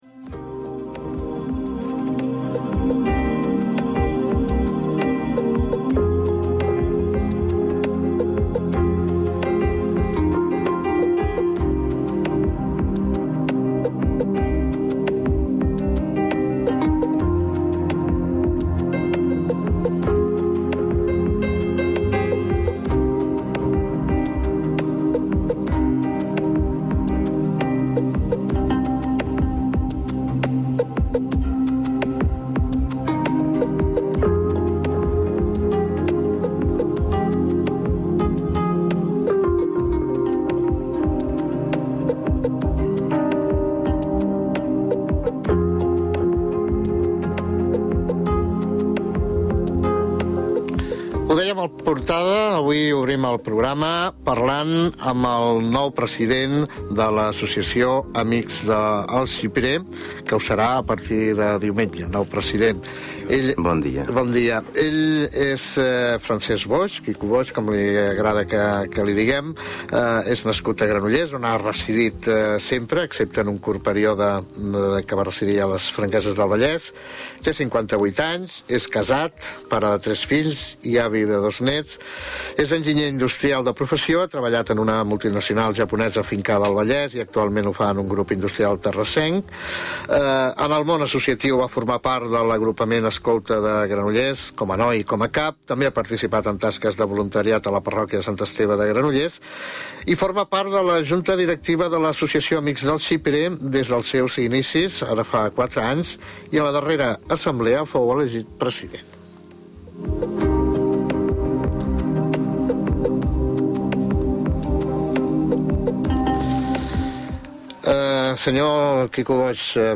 així com als espònsors i col.laboradors. Podeu escoltar l'entrevista sencera al fitxer adjunt.